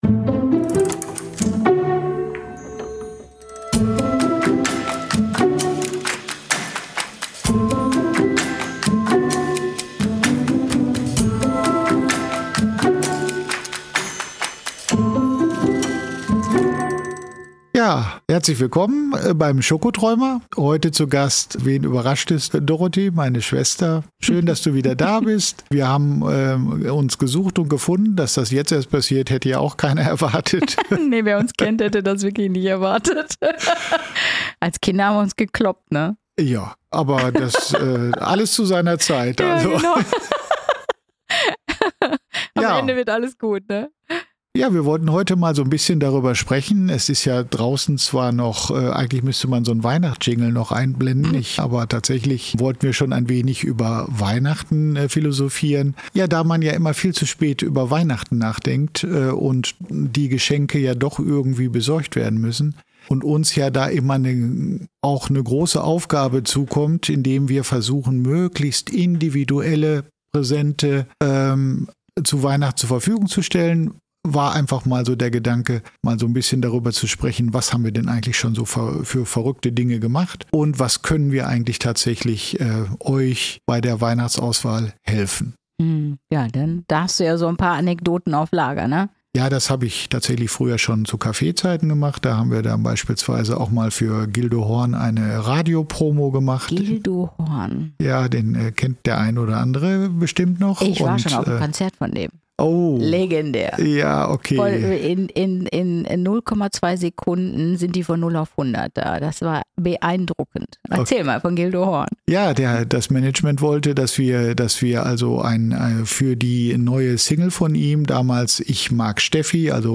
Schokoladen-Geschwister-Talk